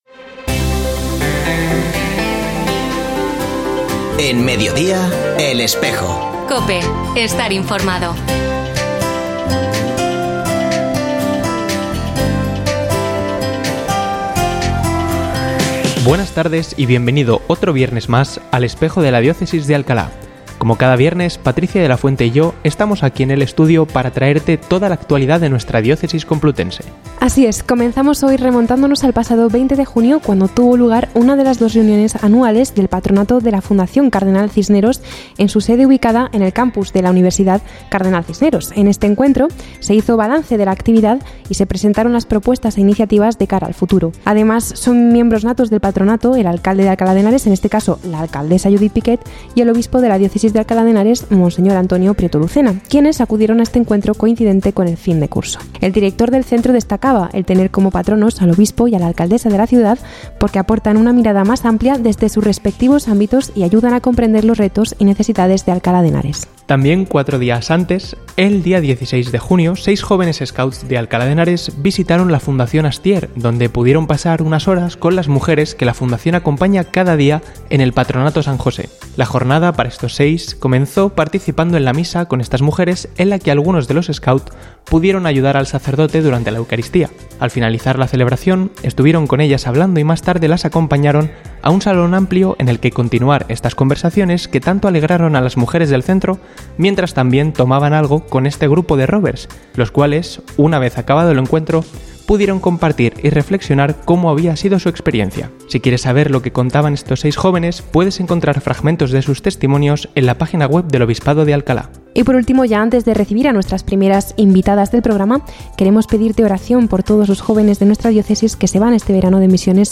Ofrecemos el audio del programa de El Espejo de la Diócesis de Alcalá emitido hoy, 5 de julio de 2024, en radio COPE. Este espacio de información religiosa de nuestra diócesis puede escucharse en la frecuencia 92.0 FM, todos los viernes de 13.33 a 14 horas.
También, como cada semana, escuchamos a don Antonio Prieto Lucena en nuestra sección semanal «El Minuto del Obispo» . Hoy nos habla del Beato Nicanor Ascanio, uno de los Mártires de Damasco, nacido en Villarejo de Salvanés, un municipio al sur de nuestra diócesis complutense.